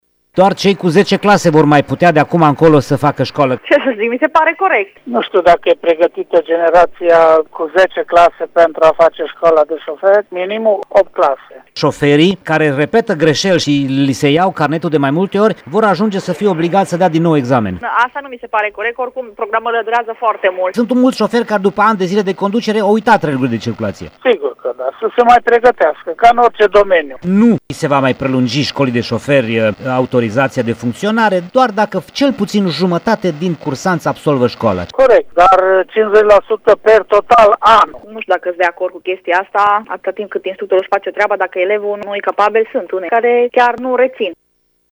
Instructorii auto au opinii împărțite legat de acest proiect legislativ: